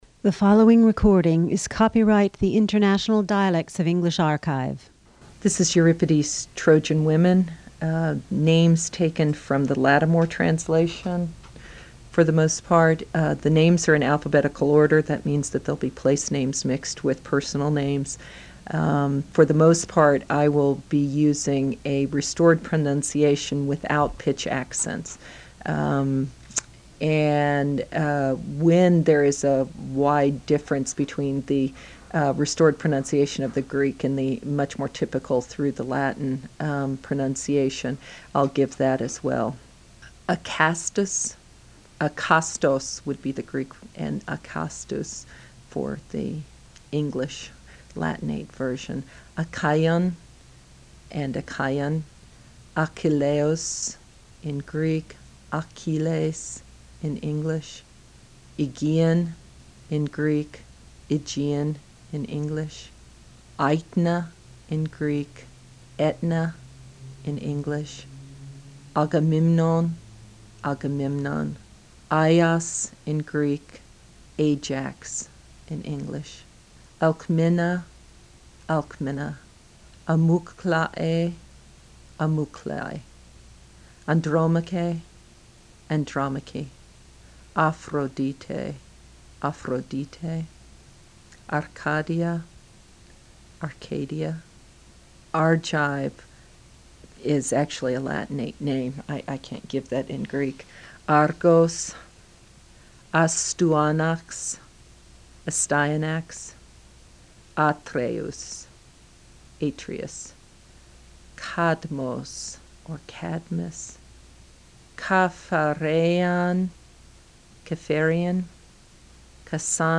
The following are reconstructed English pronunciations of the personal and place names in the play.
Acastus (a kas’ tus) Achaens (a kee’ unz) Achilles (a kil ‘ eez) Aegean (ee gee’ an) Aetna ( et’ na) Agamemnon (a ga mem’ non) Ajax (ay’ jacks) Alcmena (alk mee’ na) Amyclae (a’ my klee) Andromache (an drom’ a kee) Aphrodite (af ro di’ tee) or (af ro di’ tay) Arcadia (ar kay’ dee a) Argive ( ar’ giv) Argos (ar’ gos) Astyanax (as ty’ a naks) Atreus (at tree’ us) Cadmus (kad’ mus) Capharean (ka far’ e us) (ka fear’ e us) Cassandra ( ka san’ dra) Charybdis ( ka rib’ dis) Circe (sur’ see) Crathis (kra’ this) Cronos ( kro’ nos) Cronion ( kro’ ni on) Cyclops (sy’ klops) Cypris (sy’ pris) Danaans (da’ nay unz) Dardanus (dar’da nus) Dardanians (dar da’ ni unz) Deiphobus (dee i’ fo bus) Delos (dee’ los) Epeius of Phocis (ep’ I os of fo’sis) Euboea (yoo bee’ a) Eurotas ( yoo ro’ tas) Ganymede (ga’ ni meed) Hecate ( hek’ a tee, hek’ at) Hector (hek’tor) Hecuba (hek’ yoo ba) Helen Hellas( hel’ as) Hephaestus (he fes’ tus) ( he fees’ tus) Hera (hee’ra) Heracles((her’ a kleez) Hymen Hymenaeus (hi min ay’ oos) Ilium (il’ i um) Laconia (lay ko’ ni a) Laomedon (lay o’ me don) Lacedemonian (la si di mon’ i on) Lemnos (lem’ nos) Ligyan( Ligurian) (li’ gi an) (lig oo’ ri an) Loxias (lok’ si as) Menelaus (me ne lay’ us) (me ne lah’ us) Myconus (mi kon’ us) Neoptolemus (nay op tol’ e mus) Nereids (nee’ ree idz) Odysseus ( o dis’ yoos) Pallas Athena (pal’ as / a thee’ na) Paris (pa’ ris) Pelias (pee’ li as) Pelops(pee’ lops) Pergamum ((per’ gam um) Phoebus Apollo( fee’ bus/ a pol’ o) Phthia (thee’ a) (ftheye ‘ a) Phrygia (frig’i anz) Pirene ( pi ree’ nee) Pitana ( pit a’ na, pit a’ nay) Polyxena ((pol ik’ sen a) Poseidon (pos eye’ don) Priam ( pri’am, pree’ am) Salamis (sal’ a mis) Scamander (ska man’ der) Scyros ( skeye’ ros) Scythia ( si’ thi a) Simois (sim’oh is) Talthybius (tal thib’ i us) Telamon (tel’ a mon) Theseus (thees’ ee oos) Thessaly ( thes’ a li) Tithonus (ti thoh’ nus) Tyndareus (tin dare’ i us) Zeus (zoos)